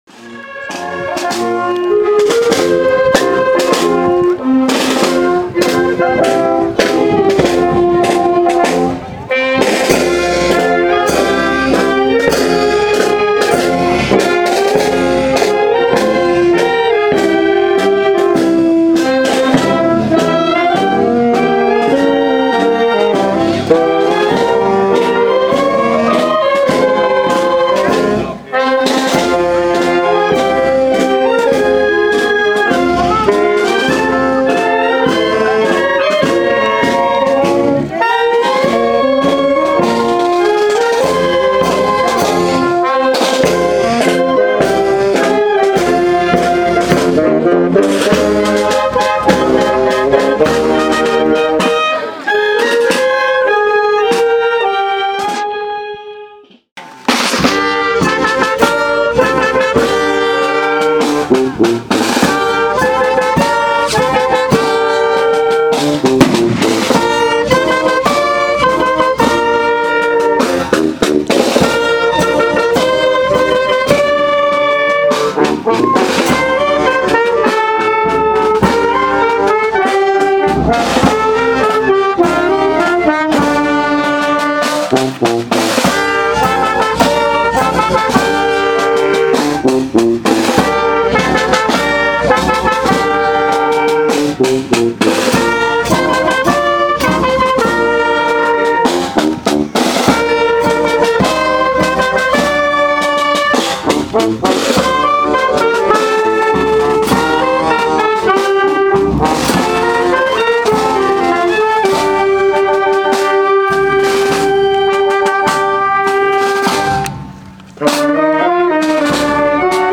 Procesión Raiguero Bajo 2016